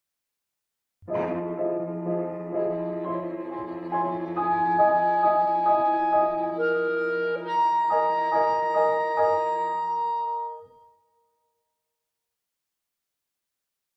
(1999) for clarinet, violin, cello, and piano. 3 minutes.